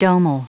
Prononciation du mot domal en anglais (fichier audio)
Prononciation du mot : domal